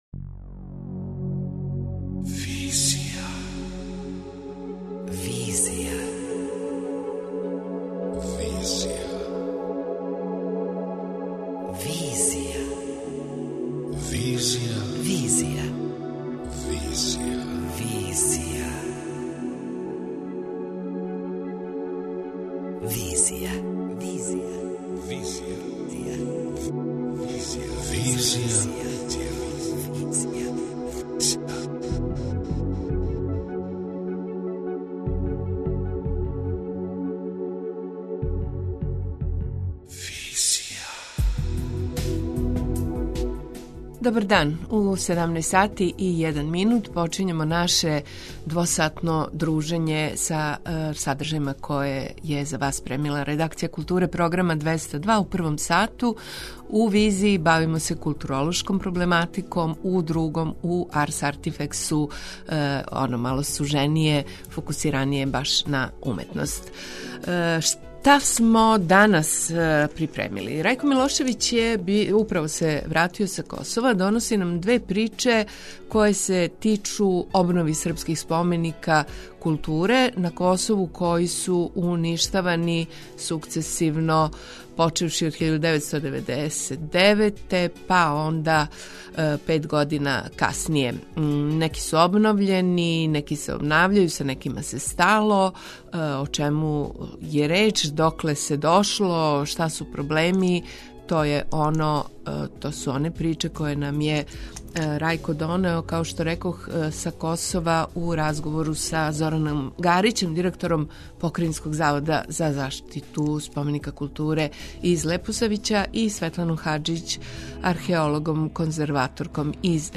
преузми : 26.58 MB Визија Autor: Београд 202 Социо-културолошки магазин, који прати савремене друштвене феномене.